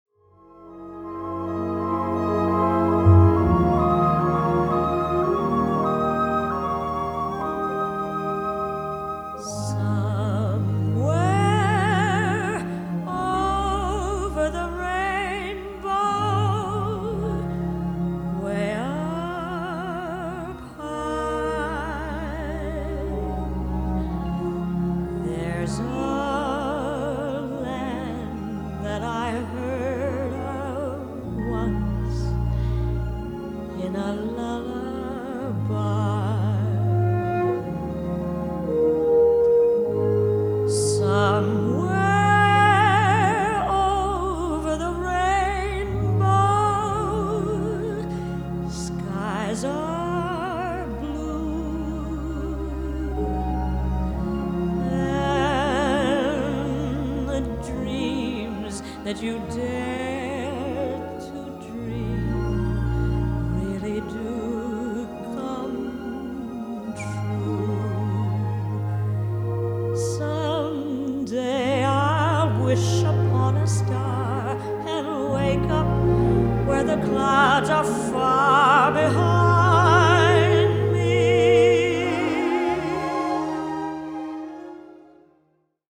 * Stereo Debut